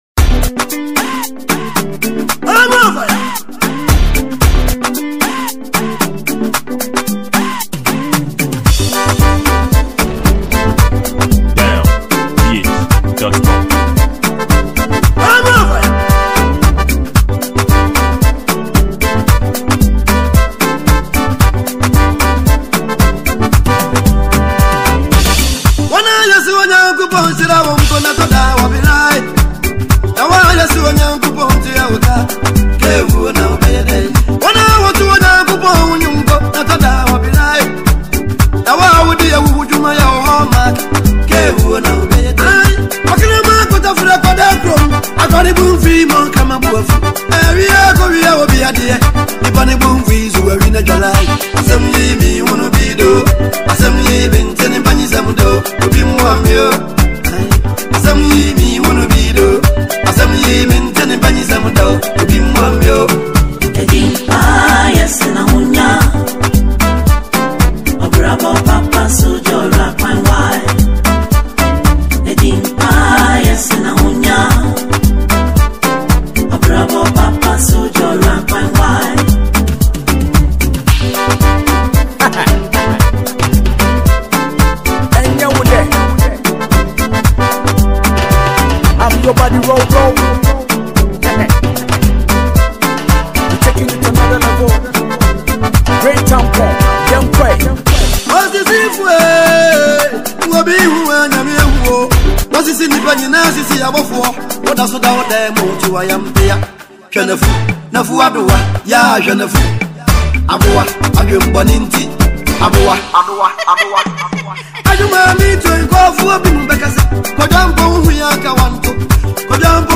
Ghanaian gospel singer.